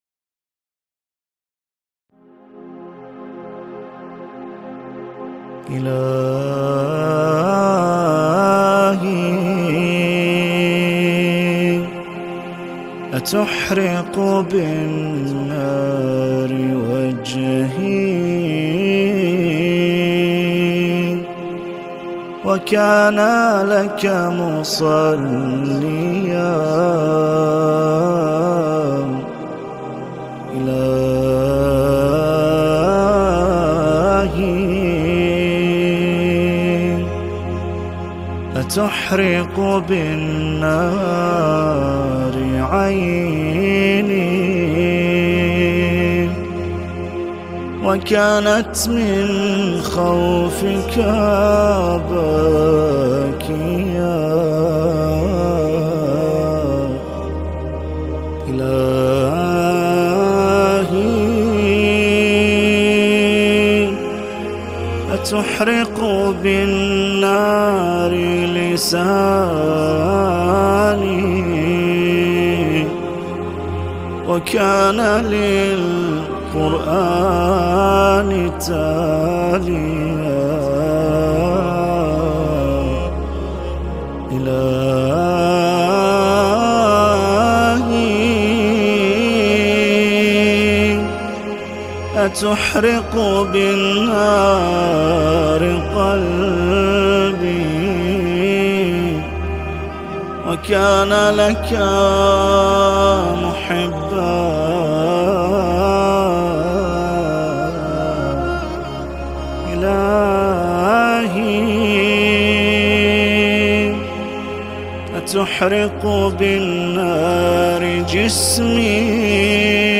نماهنگ مناجاتی
با نوای دلنشین